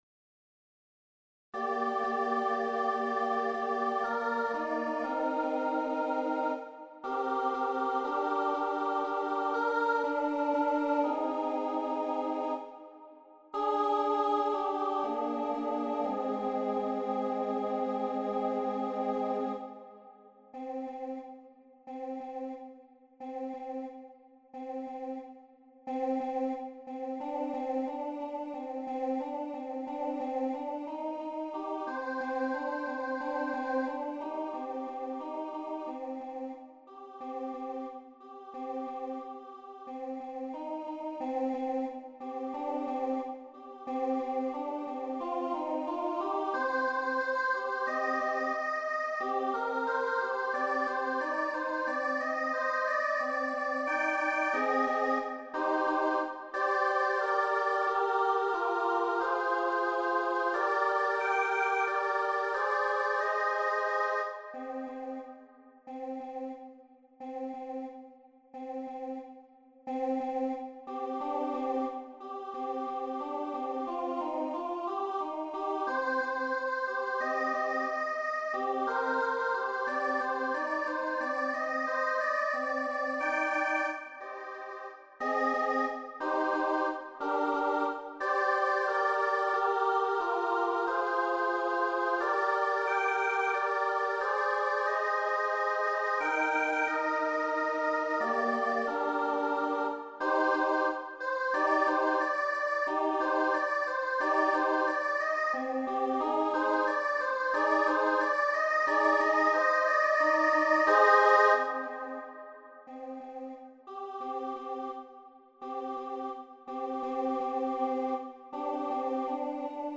SSA